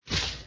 hurt3.mp3